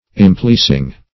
Impleasing \Im*pleas"ing\, a. Unpleasing; displeasing.